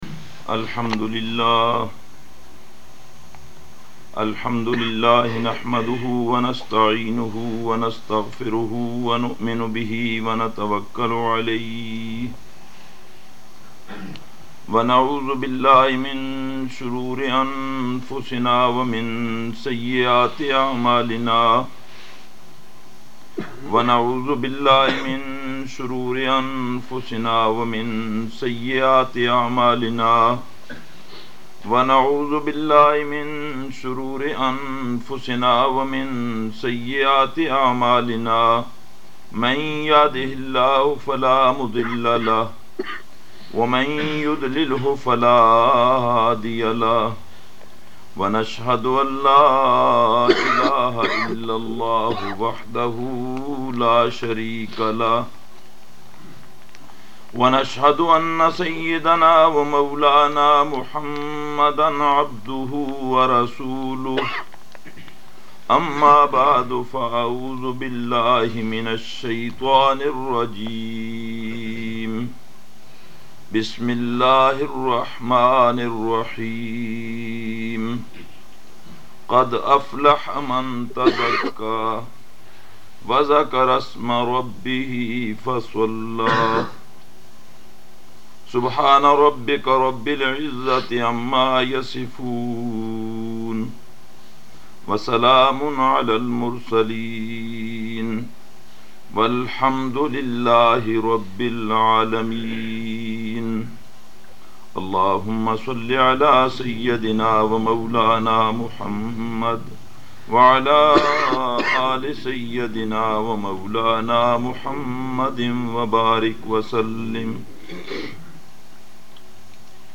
Nurul Islam Masjid, Audley Range, Blackburn - after Asar -